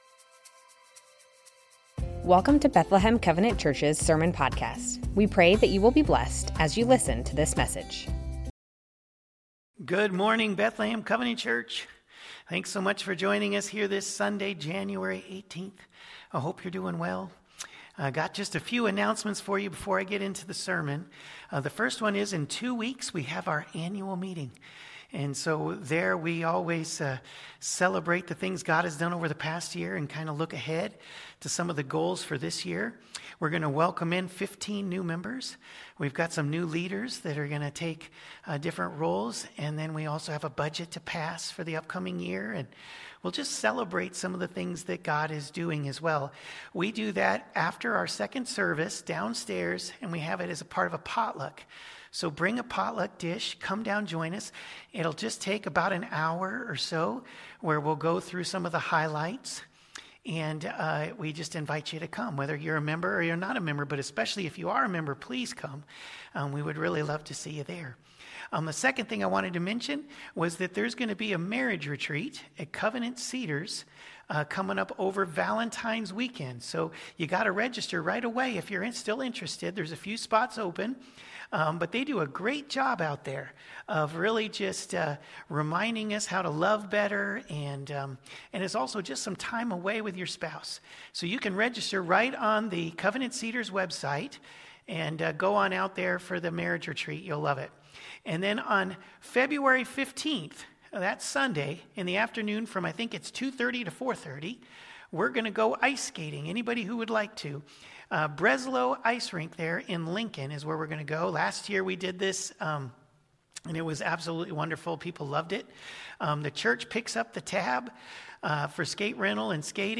Bethlehem Covenant Church Sermons The Ten Commandments - False Images Jan 18 2026 | 00:36:51 1x 00:00 / 00:36:51 Subscribe Share Spotify RSS Feed Share Link Embed